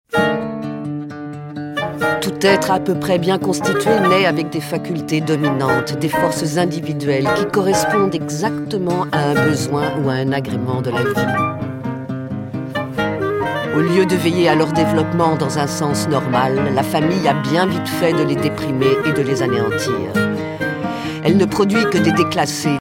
clarinette, cor de basset